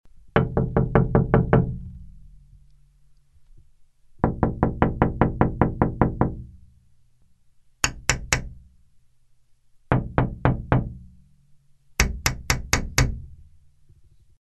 Звуки пальцев
Стук указательным пальцем по стеклу несколько раз